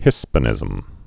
(hĭspə-nĭzəm) or His·pan·i·cism (hĭ-spănĭ-sĭzəm)